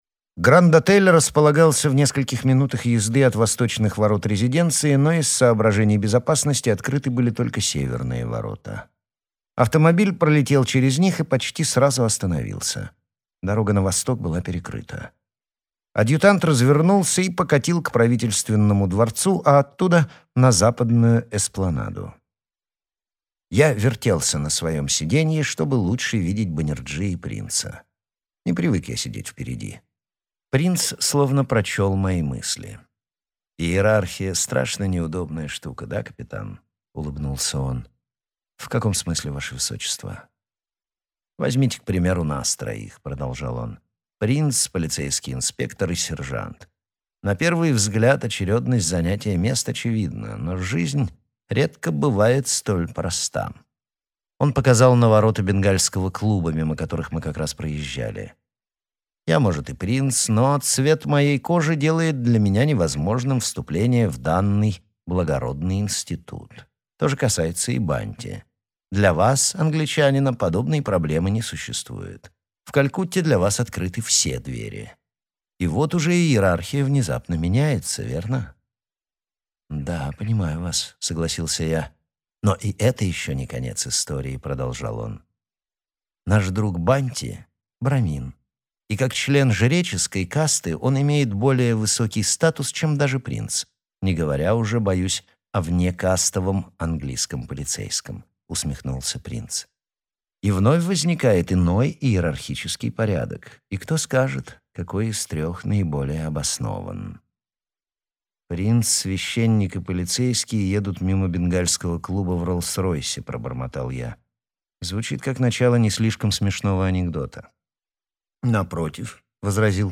Аудиокнига Неизбежное зло | Библиотека аудиокниг